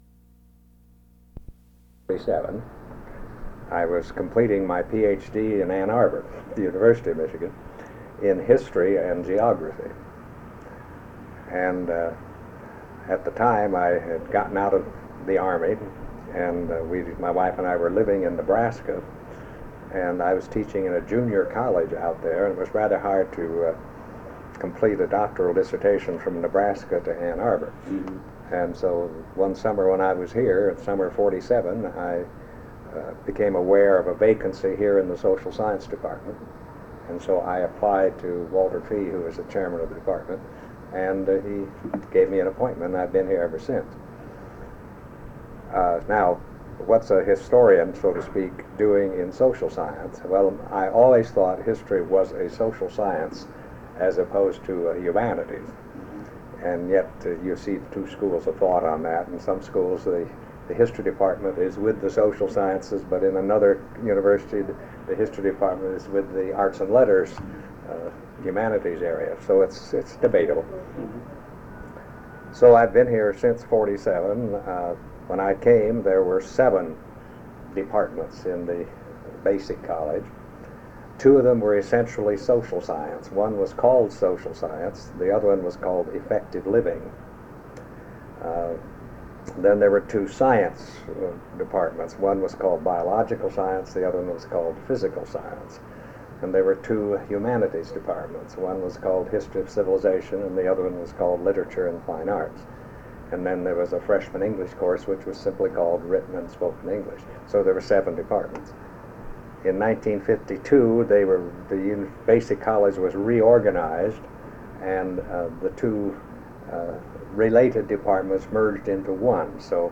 Interview
Date: June 28, 1990 Format: Audio/mp3 Original Format: Audio cassette tape Resource Identifier: A008654 Collection Number: UA 10.3.156 Language: English Rights Management: Educational use only, no other permissions given.